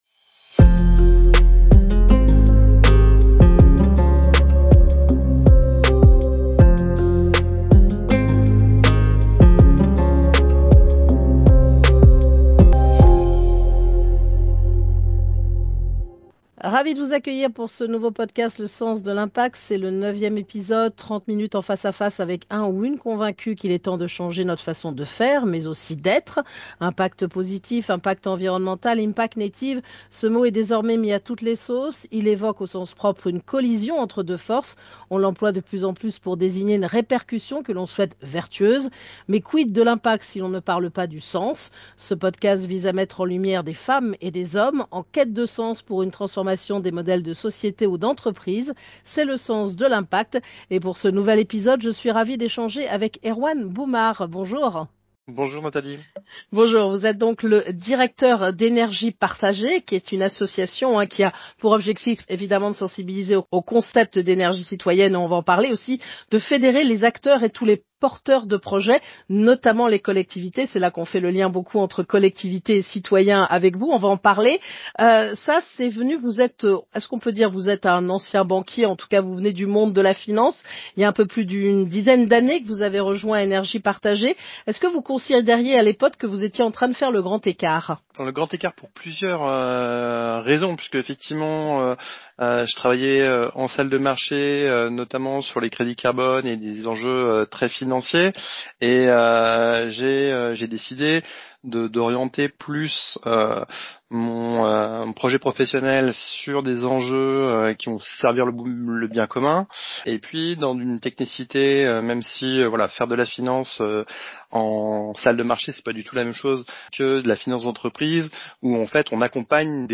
Une interview riche et inspirante, disponible sur votre plateforme d’écoute préférée, sur la page du podcast « Le sens de l’impact », ou directement ci-dessous.